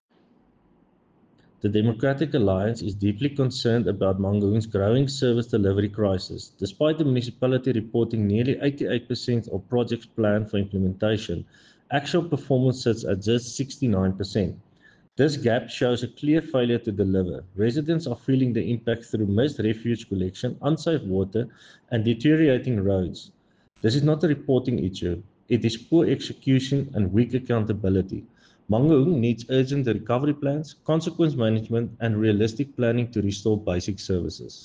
Afrikaans soundbites by Cllr Jan-Hendrik Cronje and Sesotho soundbite by David Masoeu MPL.